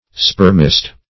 Search Result for " spermist" : The Collaborative International Dictionary of English v.0.48: Spermist \Sperm"ist\, n. (Biol.)